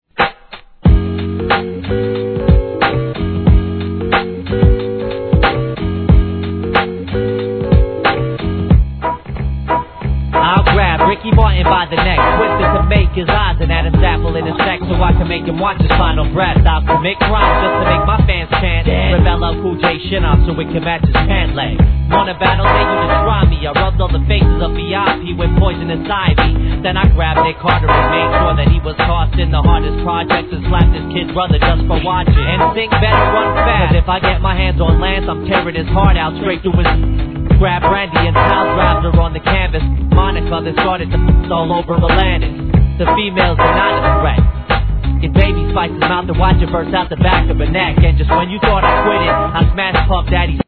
HIP HOP/R&B
スウィンギーなホーン・リフに